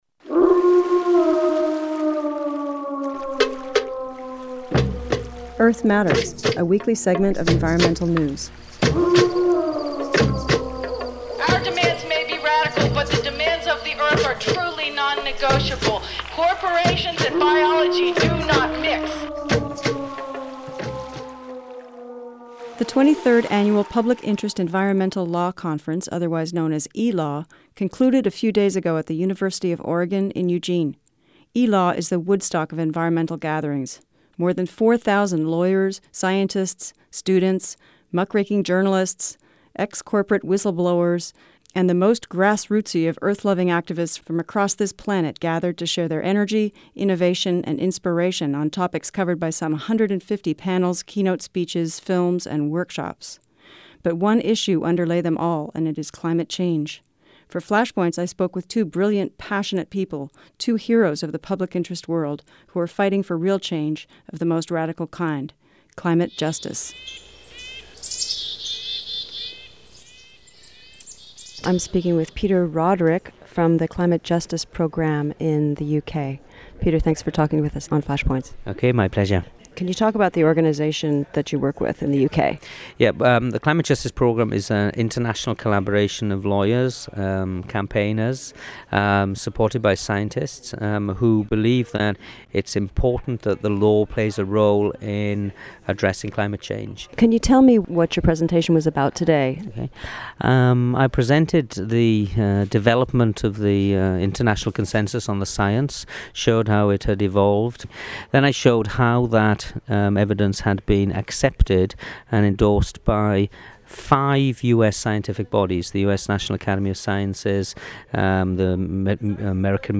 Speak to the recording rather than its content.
EARTH MATTERS is a weekly segment on KPFA radio's FLASHPOINTS. This week: Interviews from this year's Public Interest Enviro Law Conference (ELAW) in Eugene, Oregon.